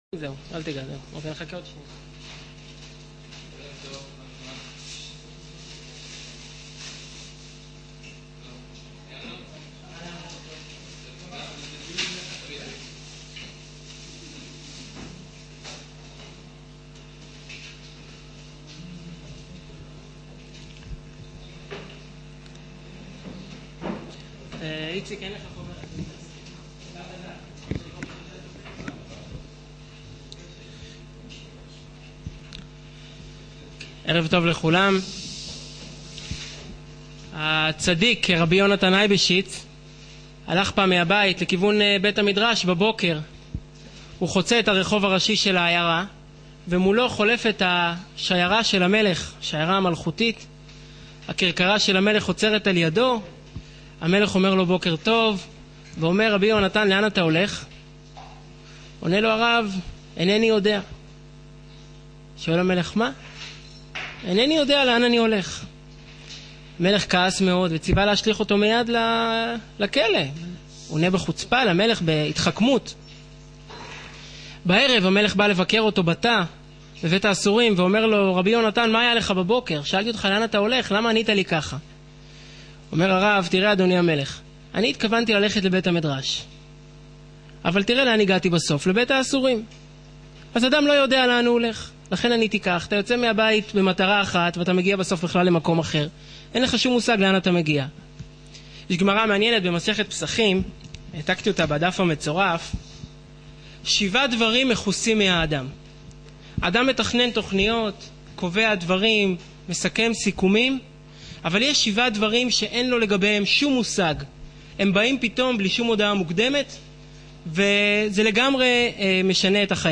מה קובע את מקום העבודה שלנו? ● שיעור וידאו